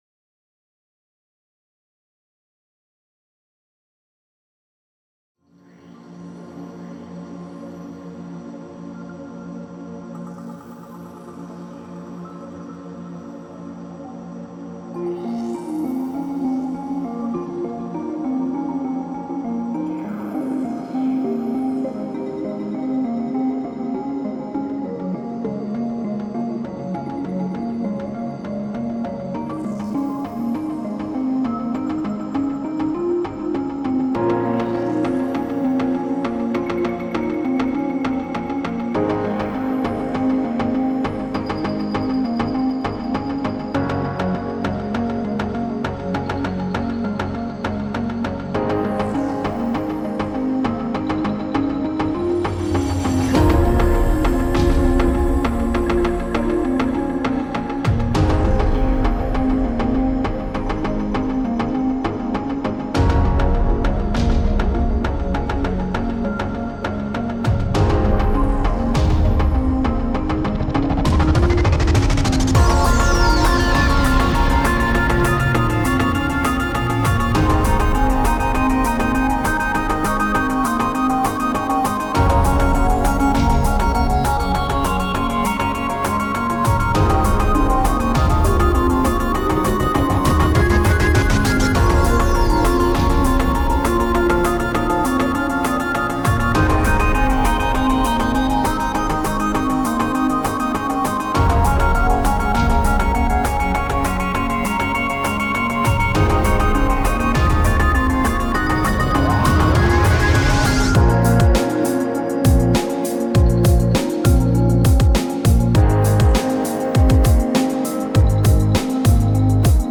_Мощная_Космическая_Музыка_
_Moschnaya_Kosmicheskaya_Muzyka_.mp3